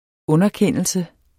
Udtale [ ˈɔnʌˌkεnˀəlsə ]